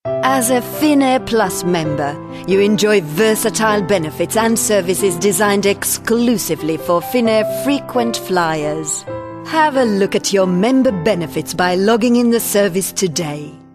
Finnish, Scandinavian, Female, 30s-50s